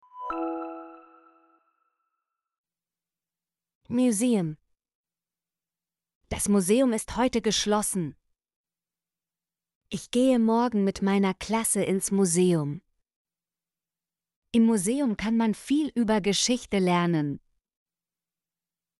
museum - Example Sentences & Pronunciation, German Frequency List